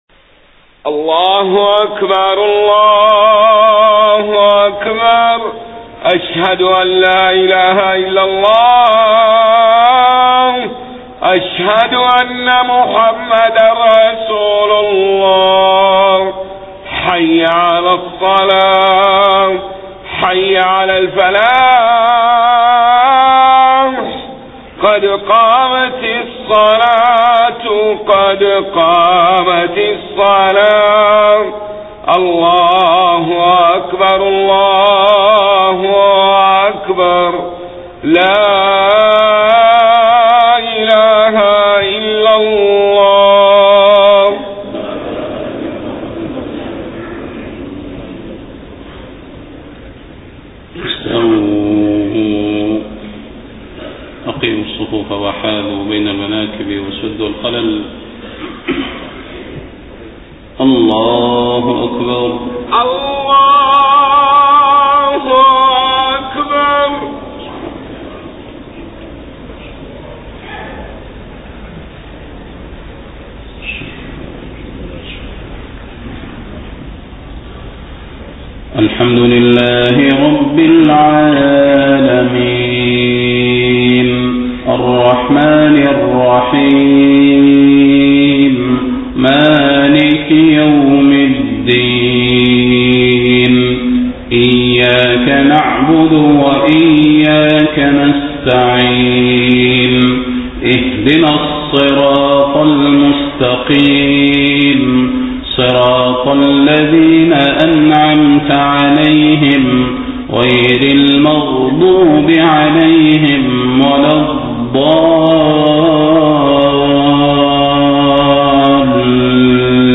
صلاة الفجر 9 ربيع الأول 1431هـ فواتح سورة ال عمران 1-18 > 1431 🕌 > الفروض - تلاوات الحرمين